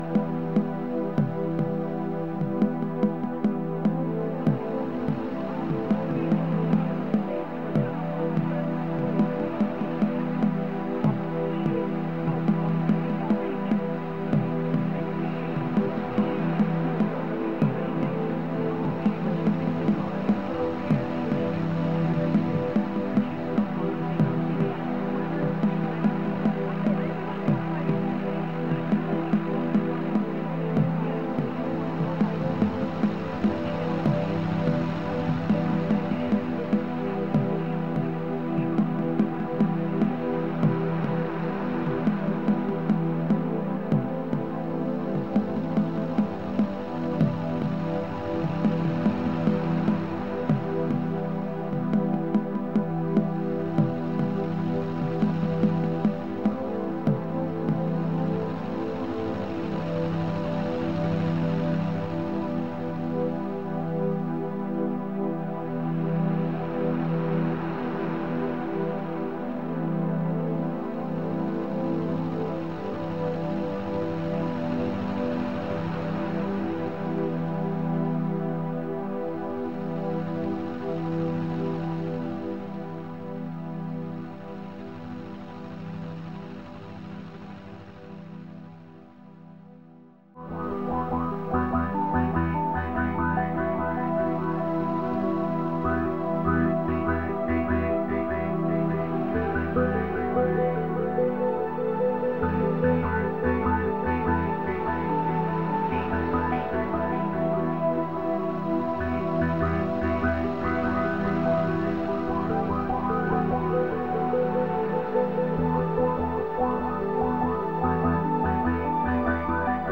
Genre Ambient , Techno , Trance